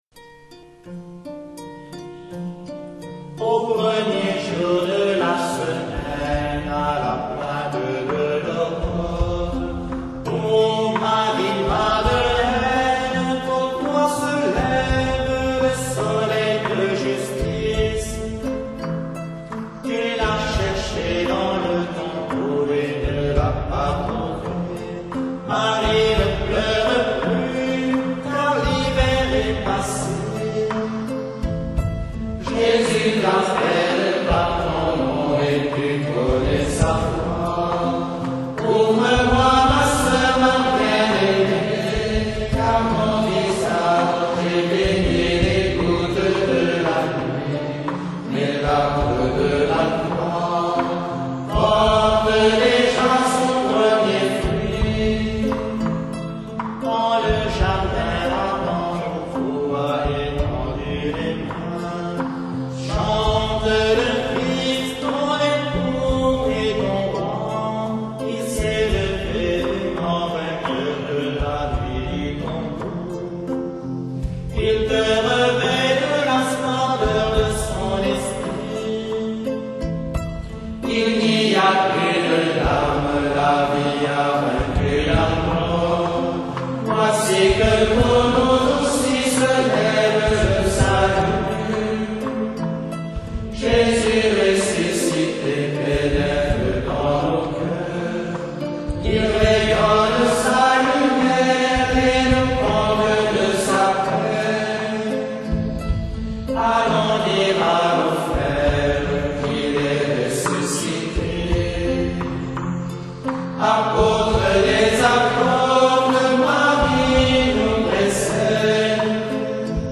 dialogue du Ressuscité avec Marie-Madeleine par les moines de Keur Moussa au Sénégal.